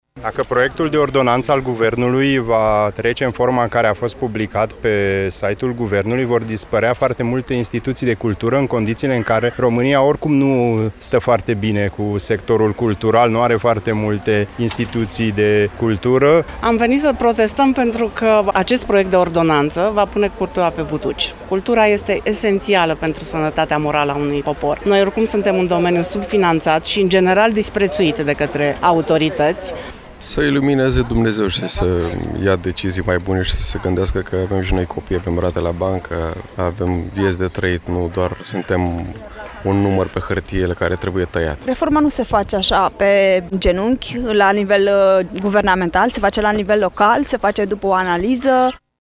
Protest al oamenilor de cultură în fața prefecturii Brașov